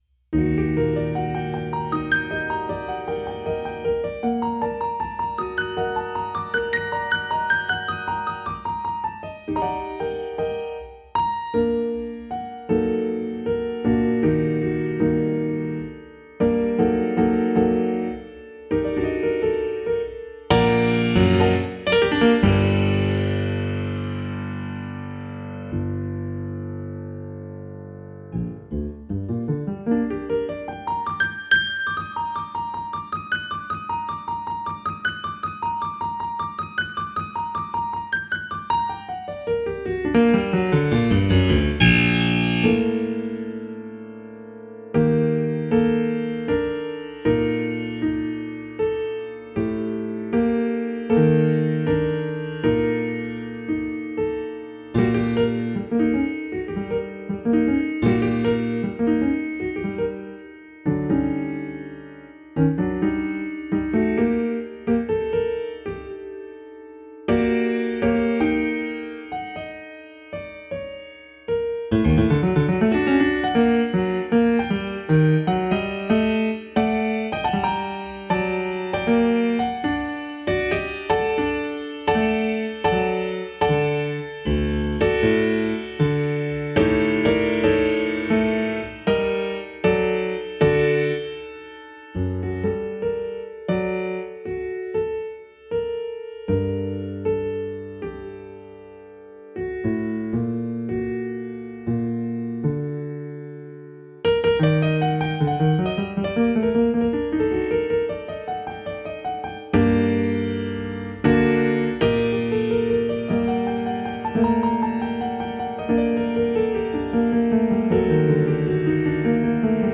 piano
neo-classical solo piano